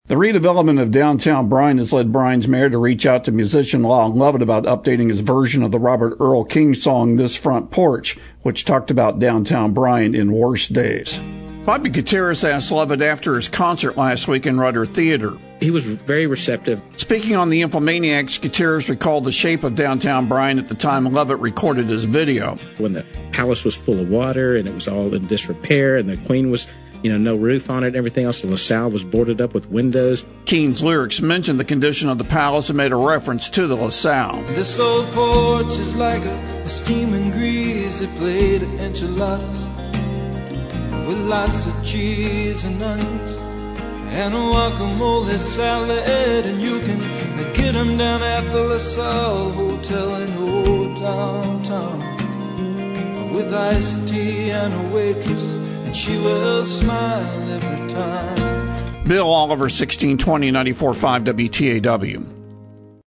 TAB 2023 Actuality: This Front Porch